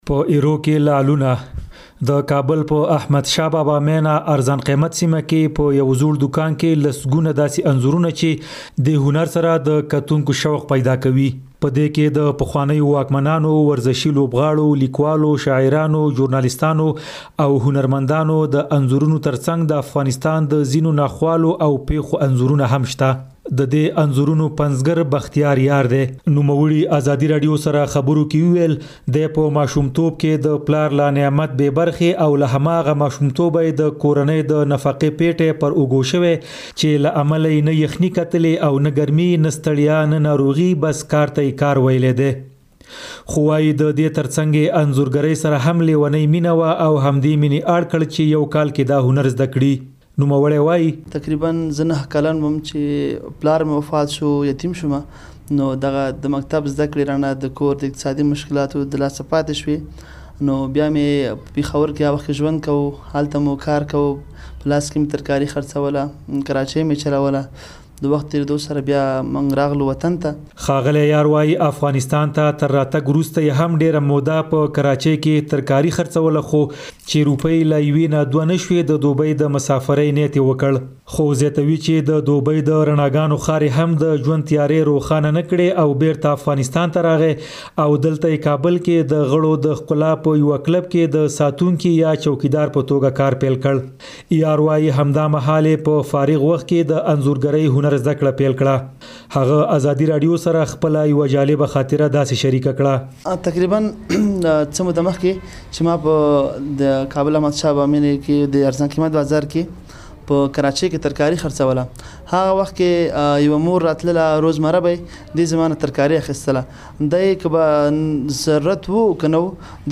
د نوموړي د ژوند پر لوړو او ژورو له هغه سره زموږ همکار د زړه خواله کړې او دا راپور يې پرې چمتو کړی دی.